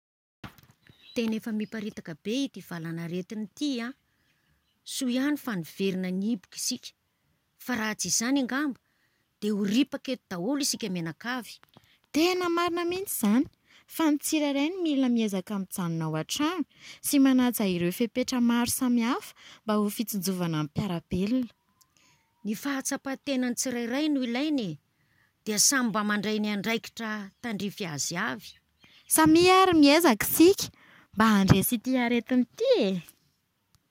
PH : Téna éfa miparitak bé ‘ty valanarétin ‘ty à, sou iani fa nivérin niibouk ‘sika fa ra tsi ‘zani angamba dé ou ripaka étou isik ménakavy.